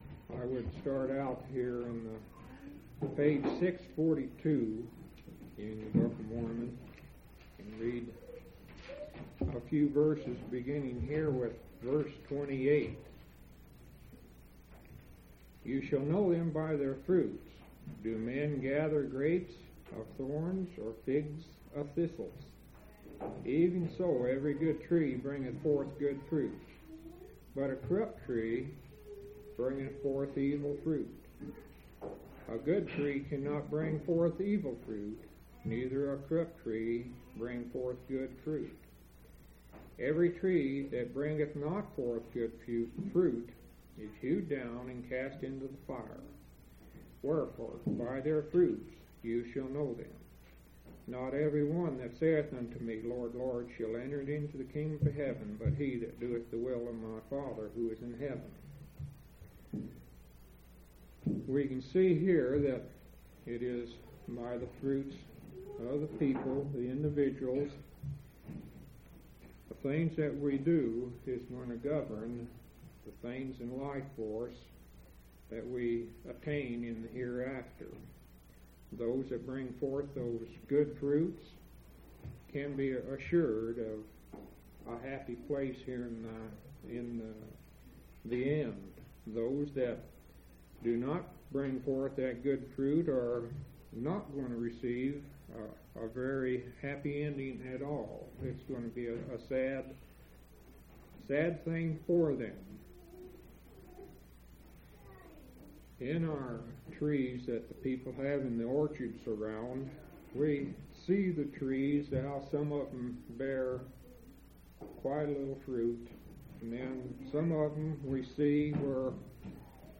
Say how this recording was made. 1/27/1985 Location: Grand Junction Local Event